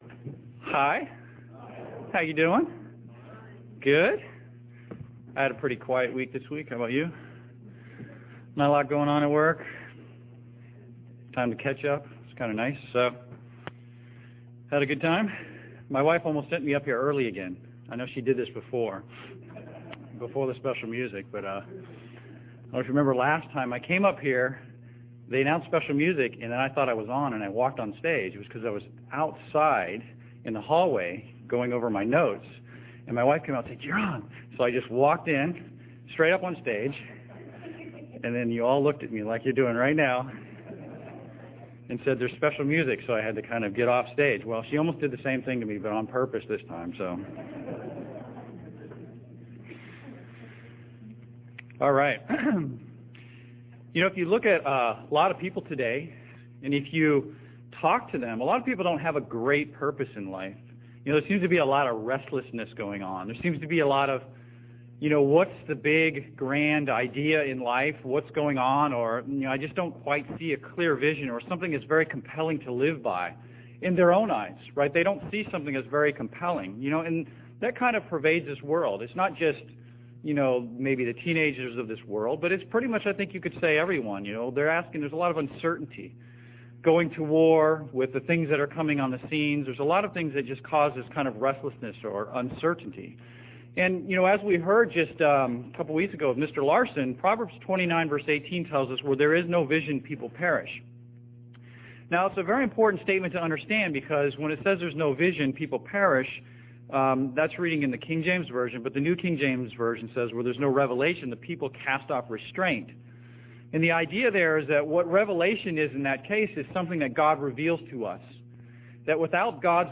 Print No Summary Available At This Time UCG Sermon Studying the bible?
Given in Seattle, WA